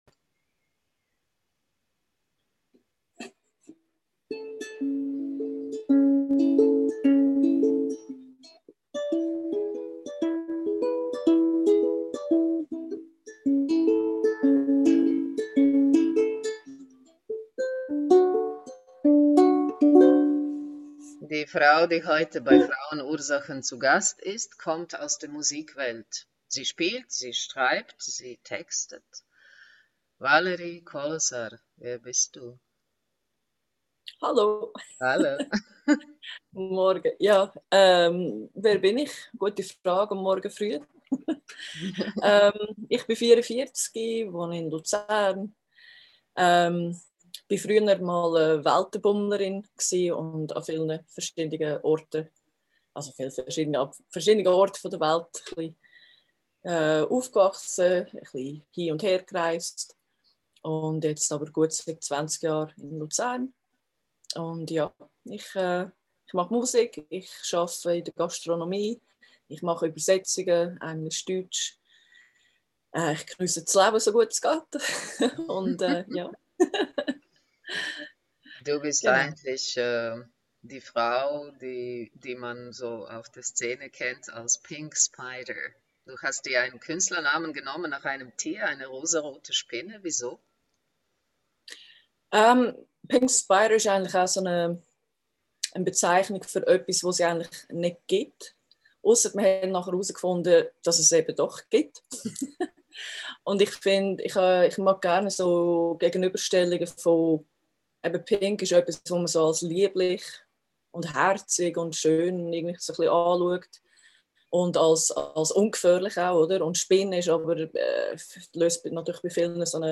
In unserem Interview